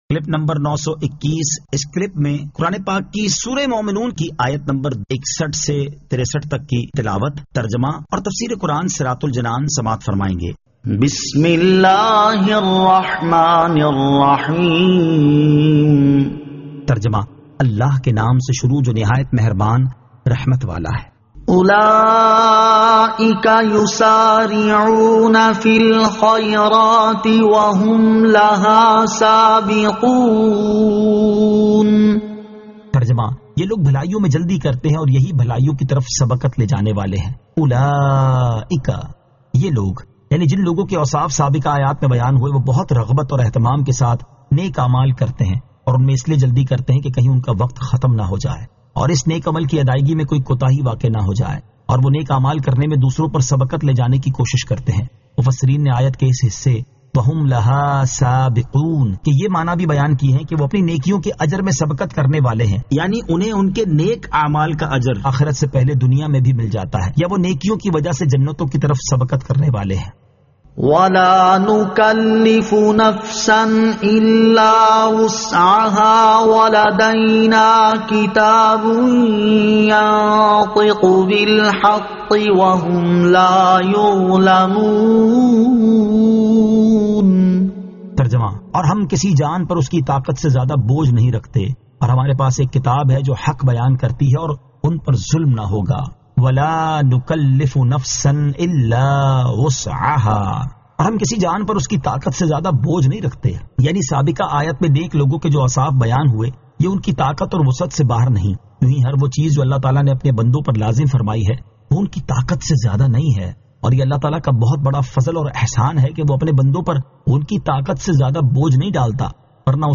Surah Al-Mu'minun 61 To 63 Tilawat , Tarjama , Tafseer